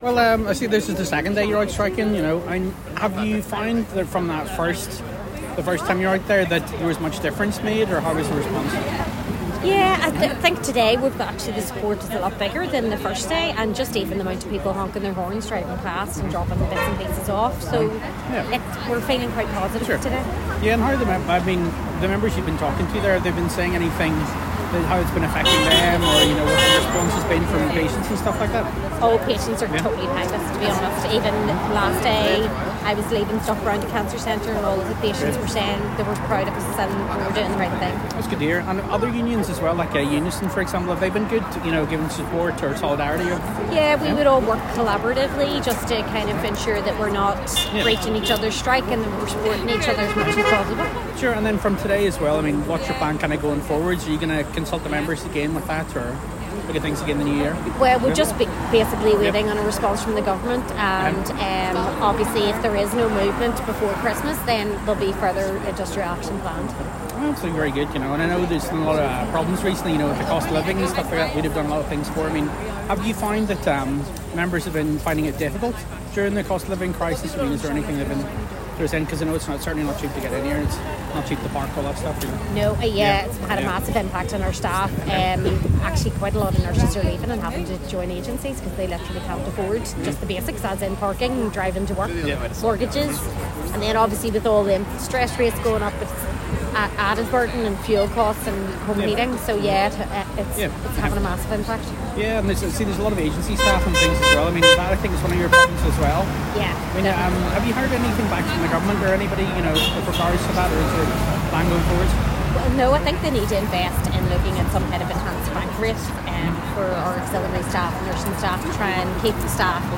As part of today's solidarity action an Royal College of Nursing RCN representative spoke to a member attending the picket line outside the Ulster Hospital in South Belfast.